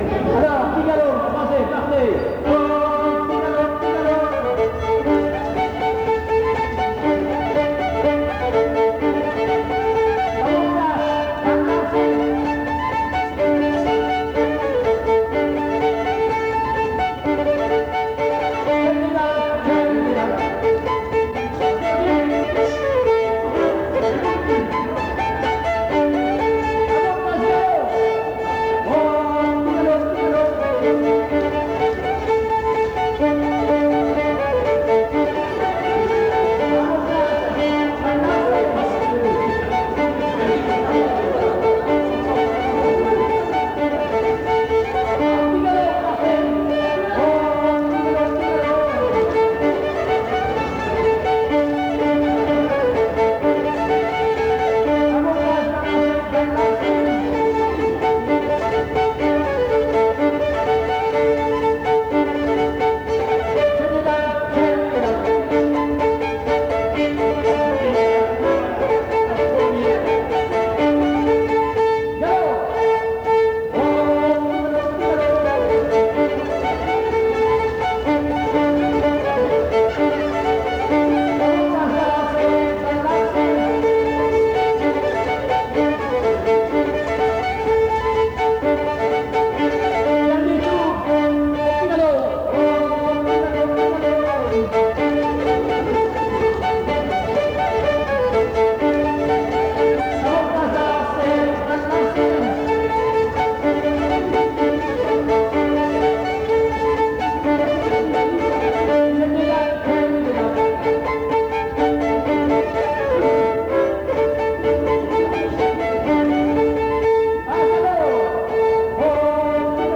danse : quadrille : petit galop
Pièce musicale inédite